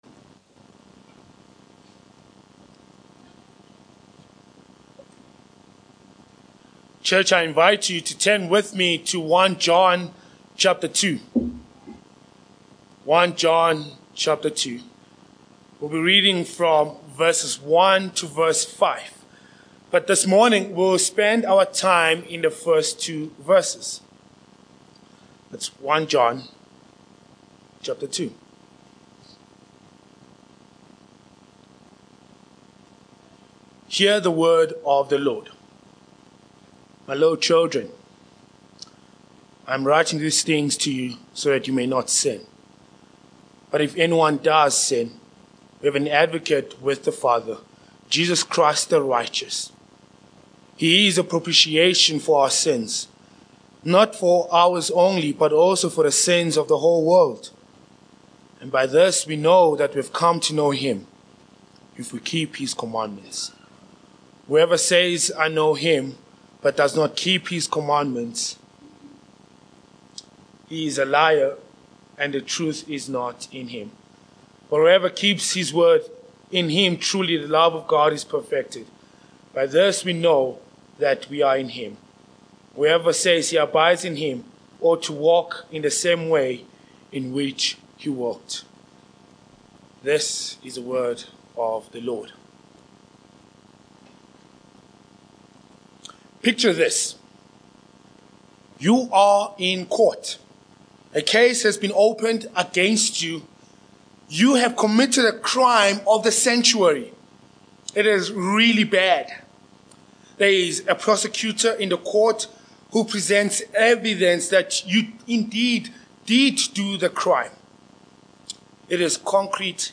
1 John 2:1-2 Service Type: Morning Passage